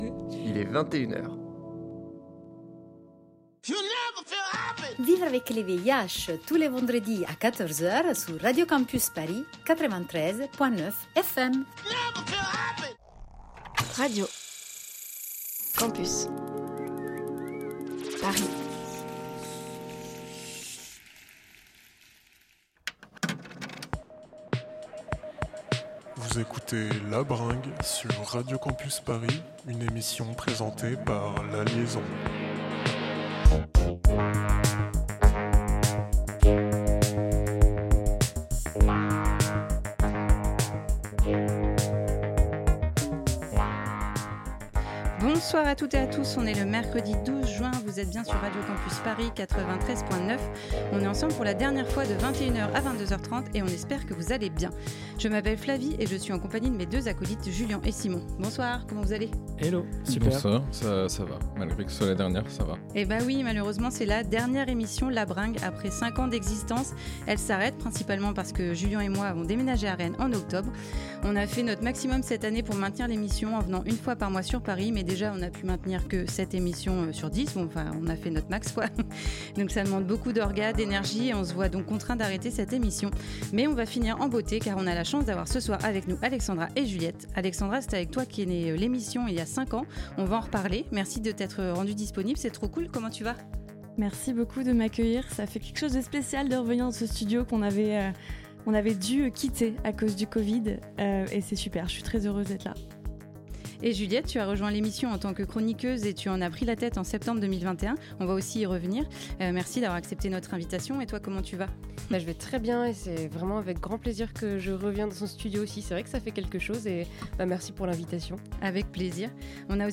Type Musicale Découvertes musicales Courants Alternatifs Pop & Rock